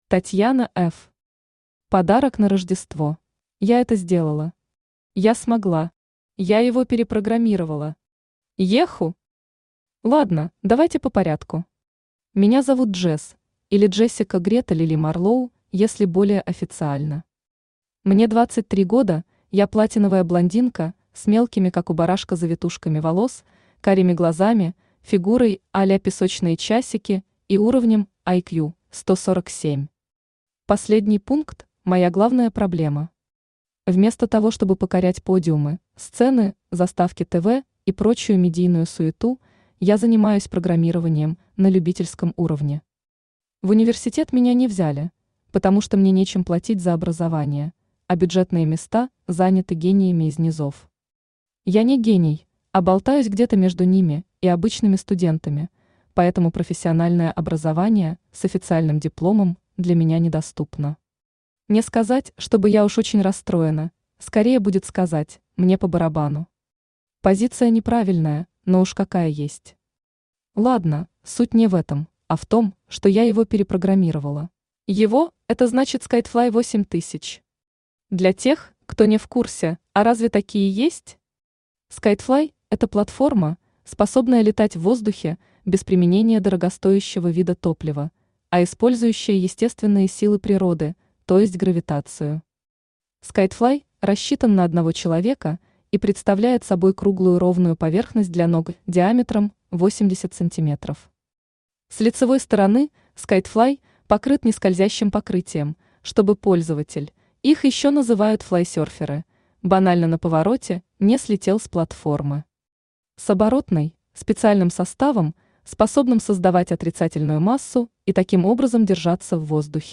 Aудиокнига Подарок на Рождество Автор Татьяна Ф Читает аудиокнигу Авточтец ЛитРес.